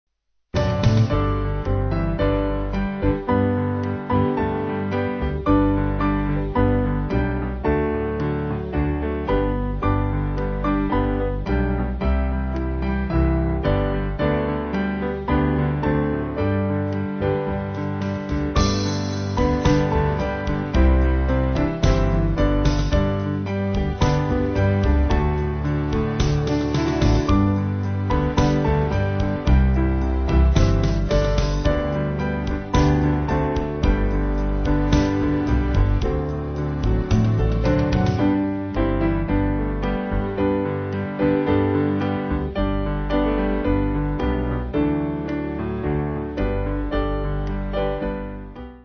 8.6.8.6 with refrain
Small Band
(CM)   4/G-Ab